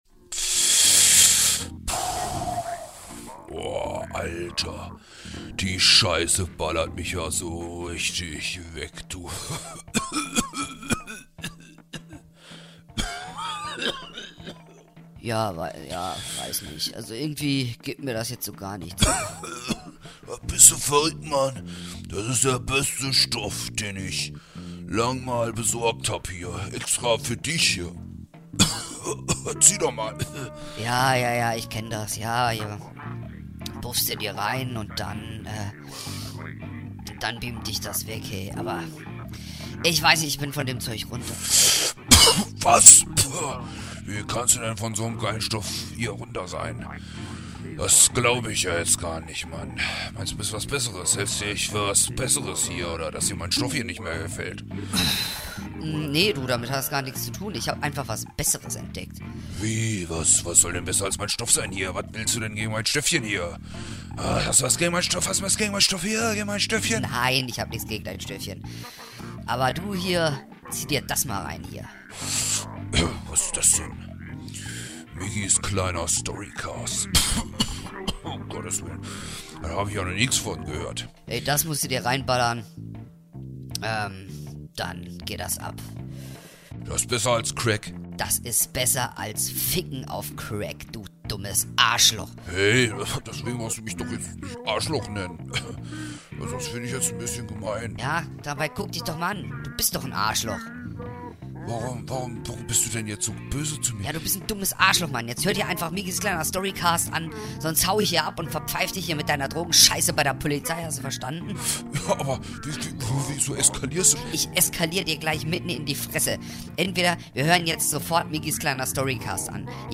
Dieses Mal liest er Kapitel 6 und 7 vor, in denen der Protagonist Dominik Aeba in abstruse Abgründe abdriftet. Doch wo ist er da hinein geraten?